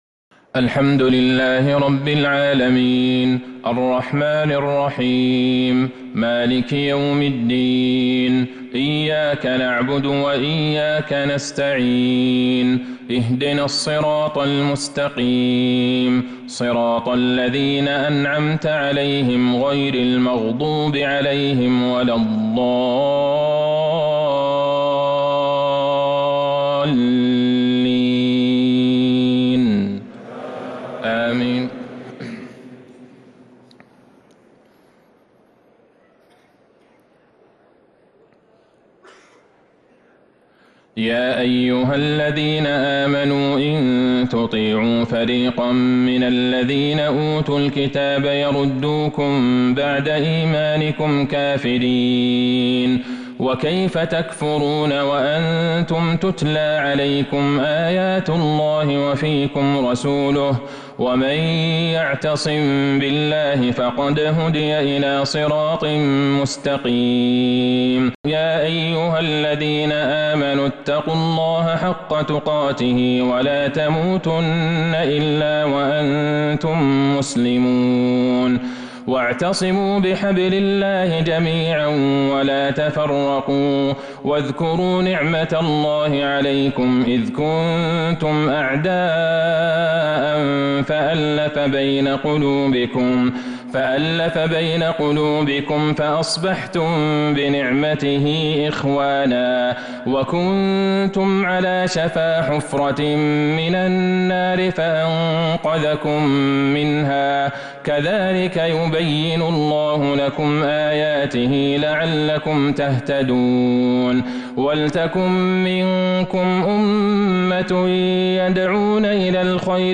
عشاء الخميس 3-8-1447هـ من سورة آل عمران 100-112| Isha prayer from surah ale-imraan > 1447 🕌 > الفروض - تلاوات الحرمين